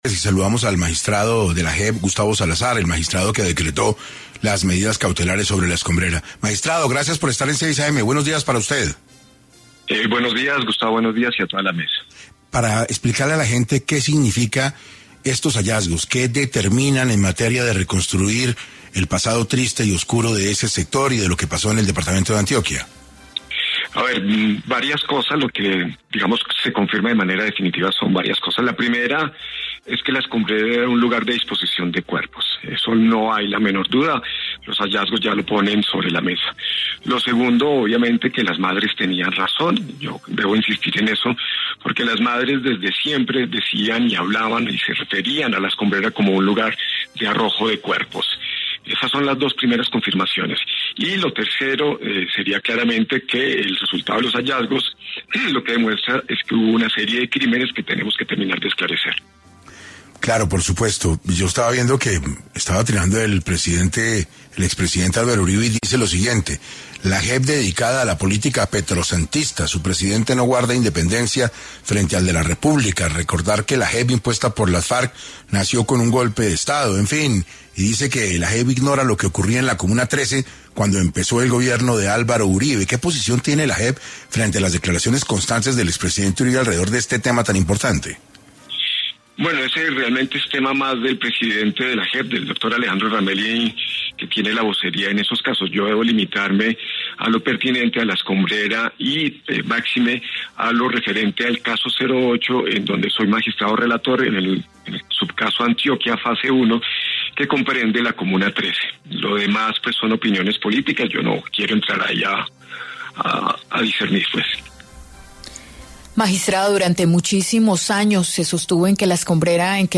Gustavo Salazar, magistrado de la JEP, confirmó en ‘6AM’ de Caracol Radio que además de La Escombrera, hubo hallazgos en Buenaventura.